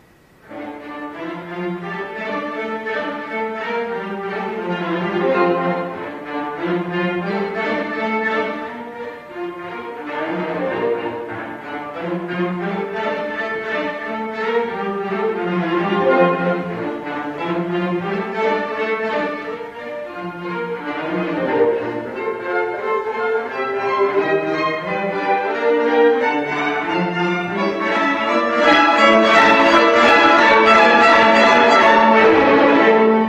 이 악장은 D단조의 타란텔라 형식의 론도 소나타 형식이다.
이 주제는 전통적으로 점 음표 구절의 일반적인 활 긋기와 반대 방향으로 활을 긋는다.[13] 이것은 강세를 여린 박으로 옮기는 효과를 내어, 전체 구절에 절뚝거리는 춤의 특징을 부여한다.
마지막 악장의 주제